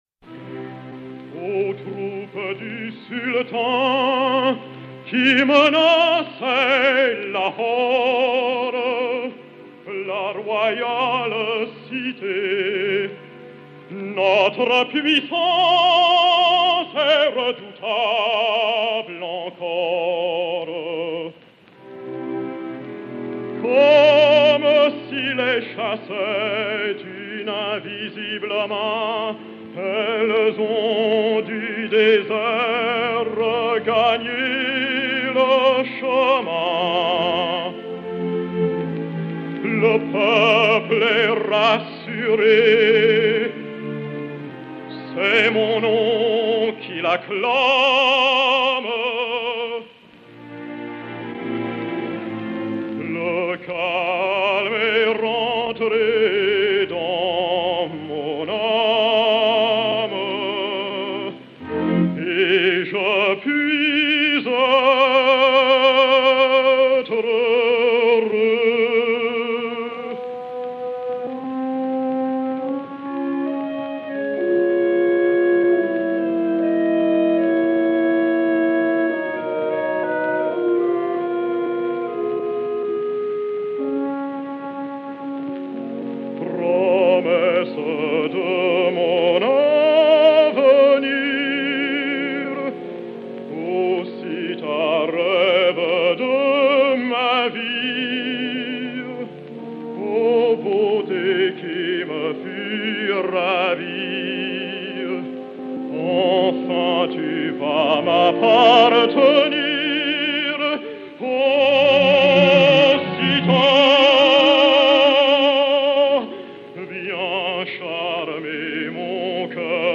soprano américain
Ada Adini (Salomé) et Piano
XPh 580, enr. à Milan en 1905